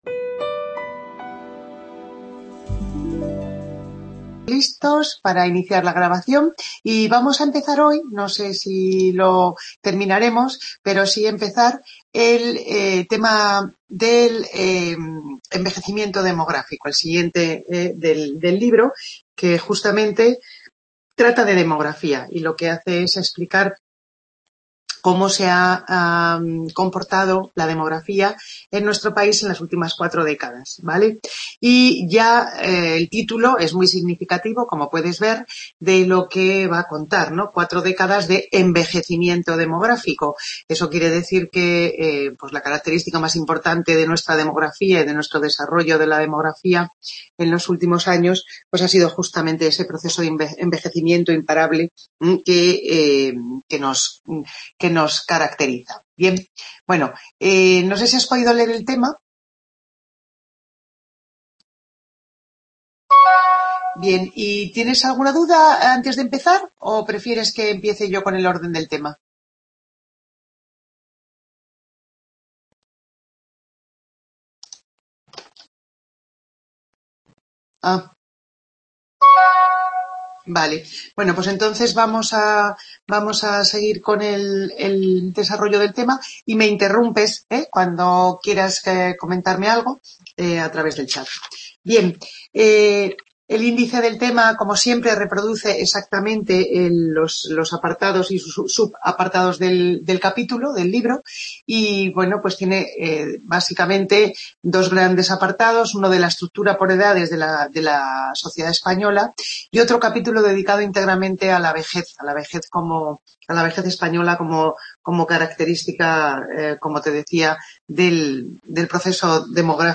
CLASE 19/11/2020